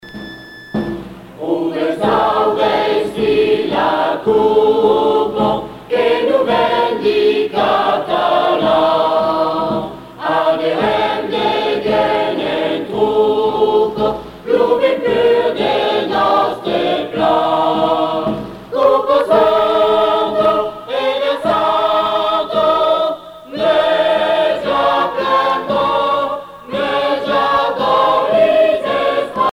Chants et danses traditionnelles de Provences
Pièce musicale éditée